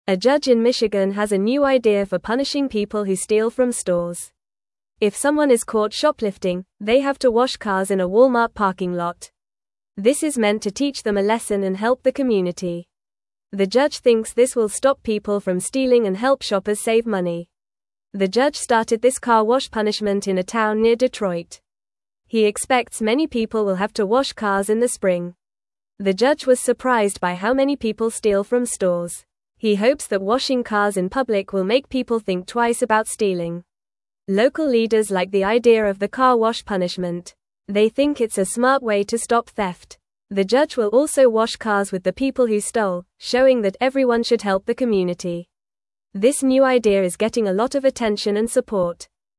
Fast
English-Newsroom-Lower-Intermediate-FAST-Reading-Judges-New-Plan-to-Stop-Store-Stealing.mp3